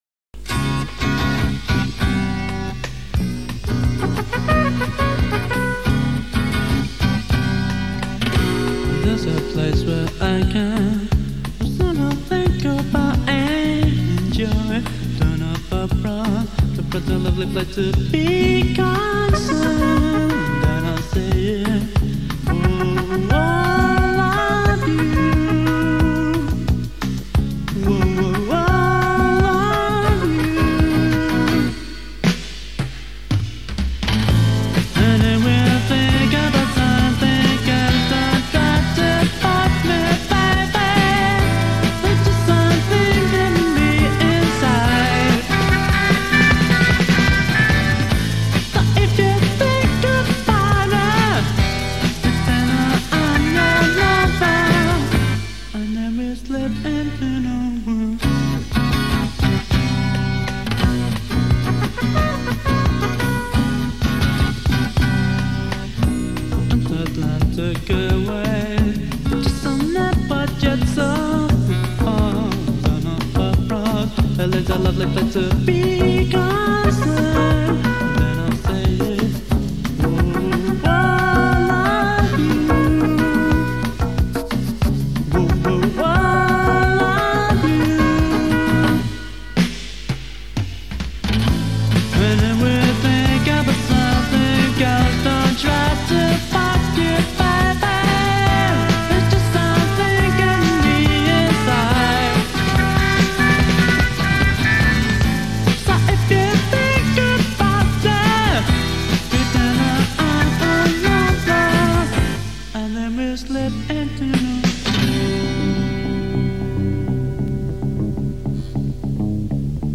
Post-Punk/Indie-Pop band